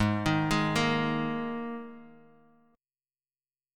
G#sus2 chord